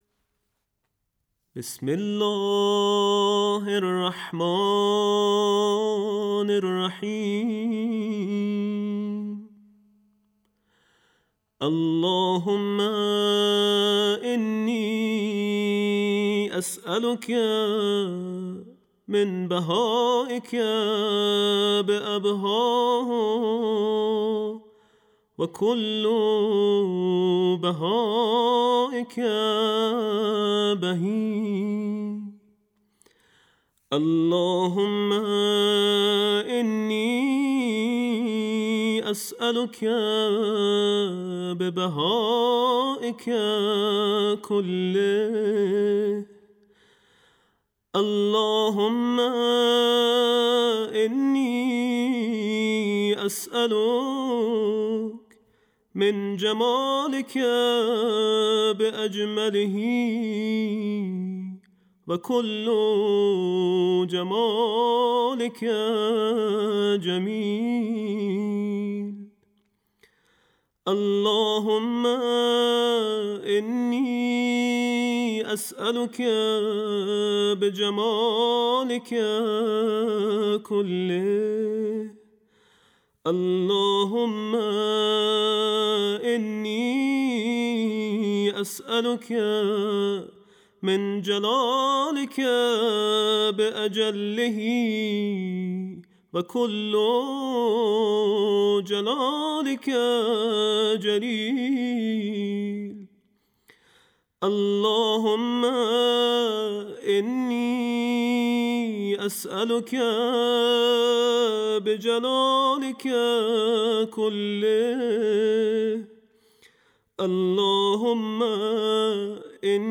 دعای سحر دعایی است که توصیه شده در سحرهای ماه مبارک رمضان خوانده شود